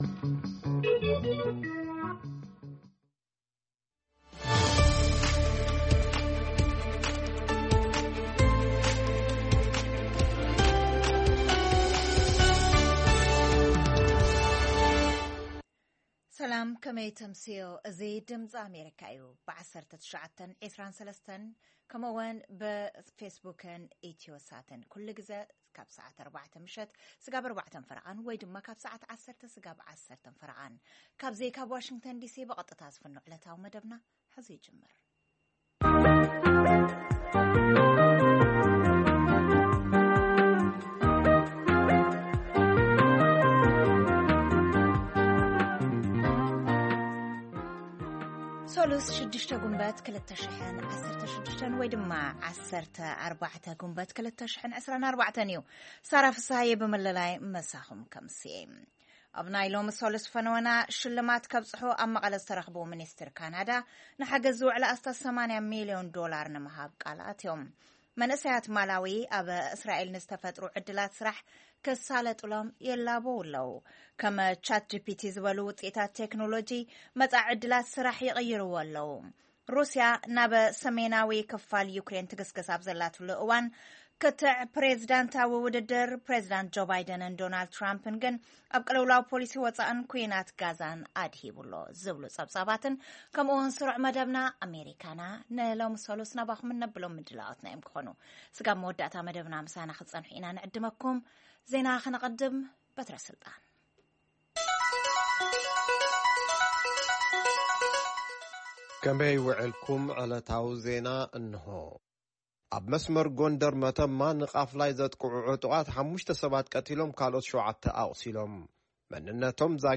ፈነወ ትግርኛ ብናይ`ዚ መዓልቲ ዓበይቲ ዜና ይጅምር ። ካብ ኤርትራን ኢትዮጵያን ዝረኽቦም ቃለ-መጠይቓትን ሰሙናዊ መደባትን ድማ የስዕብ ። ሰሙናዊ መደባት ሰሉስ፡ ኤርትራውያን ኣብ ኣመሪካ/ ኣመሪካና